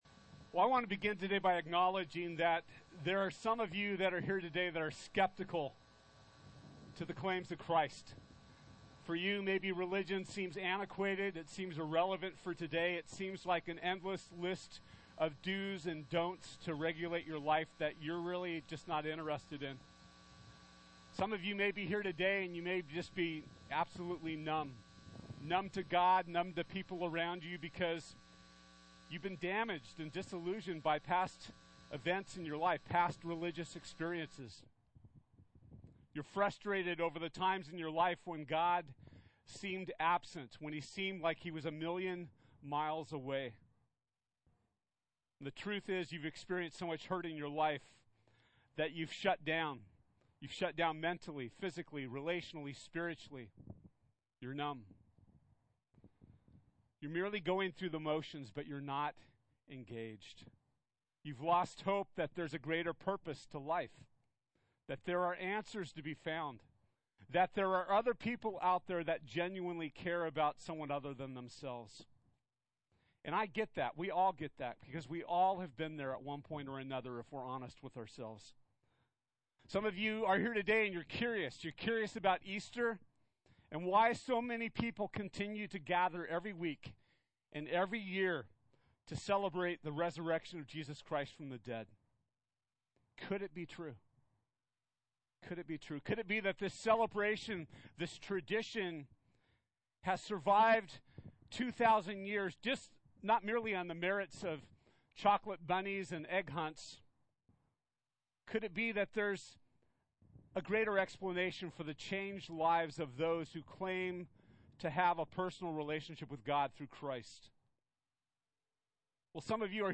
This year, we celebrate Easter together with a family-friendly service at Father Serra Cross / Grant Park to celebrate the NEW LIFE that Jesus secured for us.